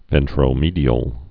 (vĕntrō-mēdē-əl)